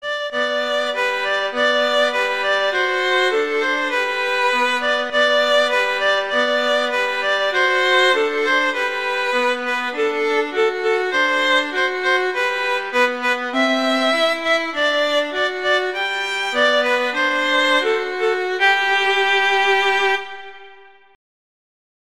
arrangements for two violins
two violins